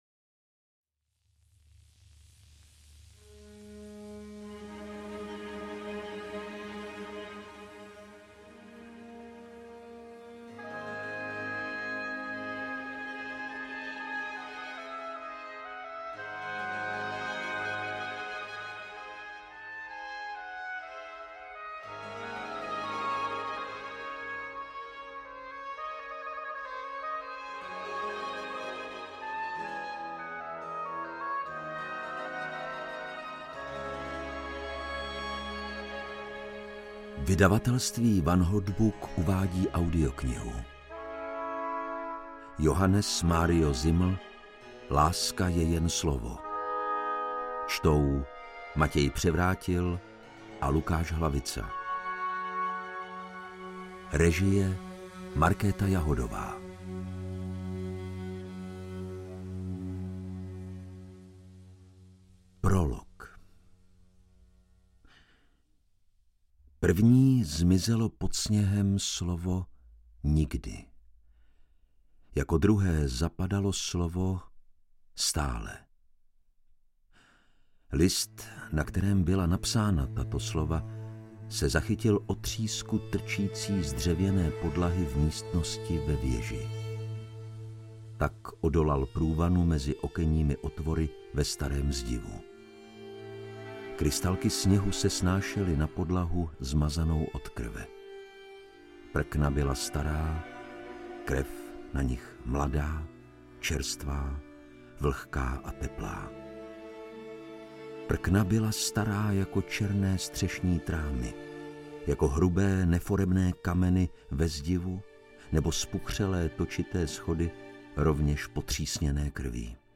AudioKniha ke stažení, 63 x mp3, délka 22 hod. 44 min., velikost 1220,0 MB, česky